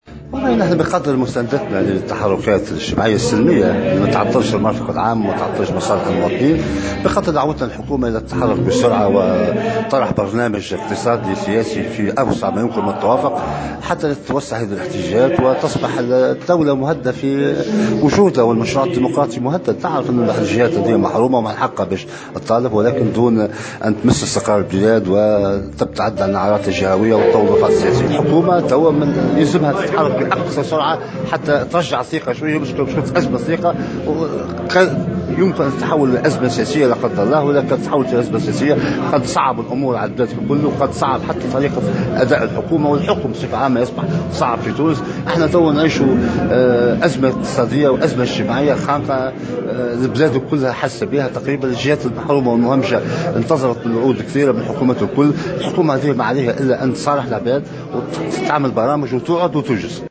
المباركي الذي أشرف اليوم على تظاهرة عمالية بقفصة بمناسبة عيد الشغل، دعا في تصريح لمراسل الجوهرة أف أم، الحكومة إلى التحرك السريع لاسترجاع الثقة فيها من خلال مصارحة المواطنين وتقديم الحلول والإيفاء بالوعود قبل أن تصبح الأمور أكثر تعقيدا، وفق تعبيره.